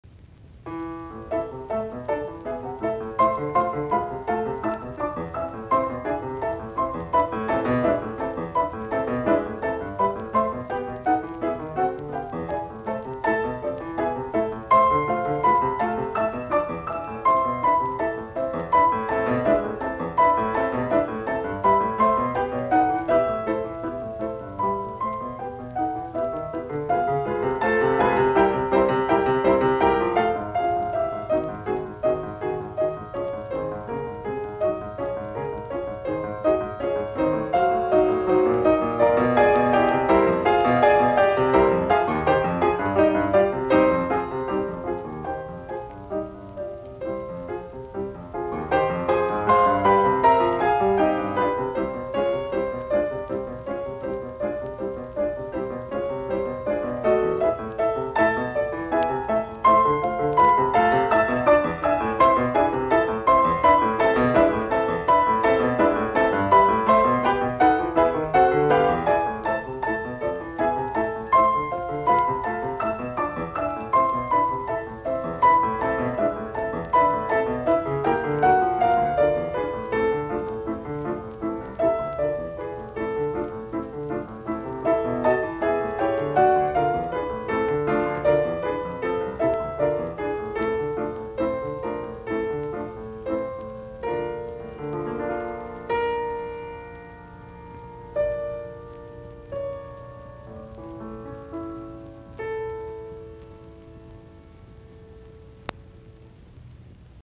Etydit, piano, op25